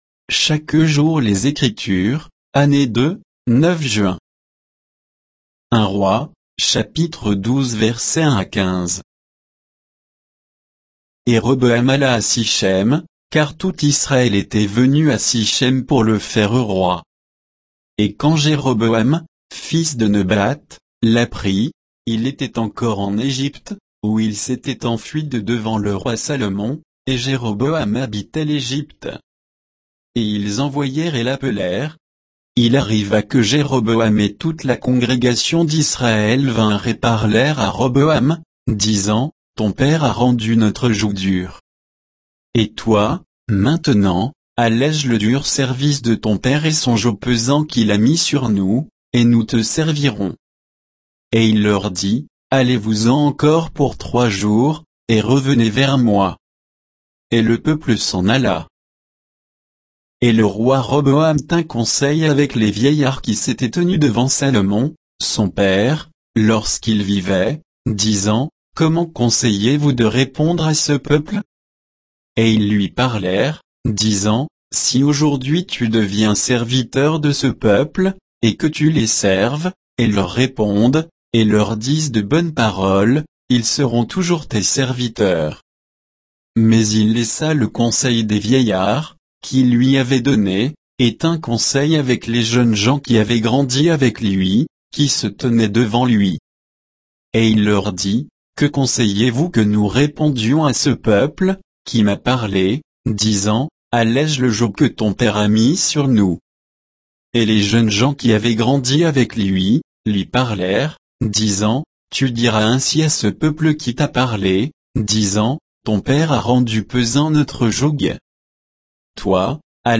Méditation quoditienne de Chaque jour les Écritures sur 1 Rois 12, 1 à 15